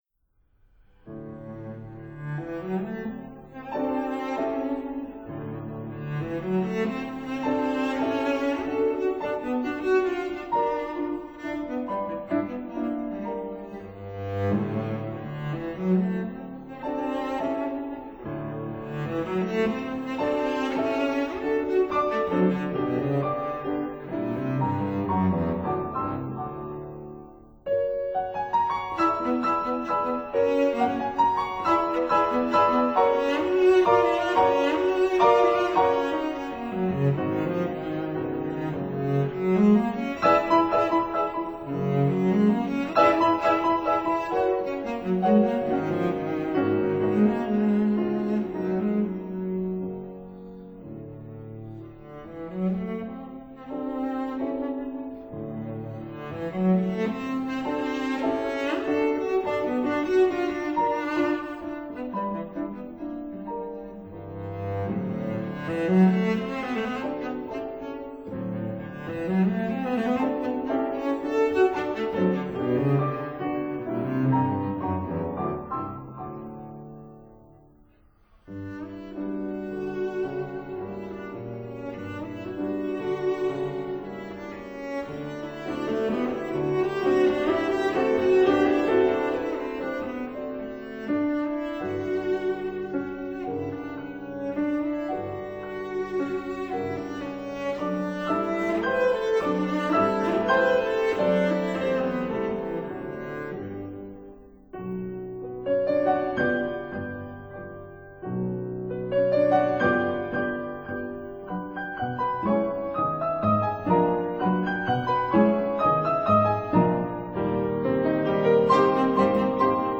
cello
piano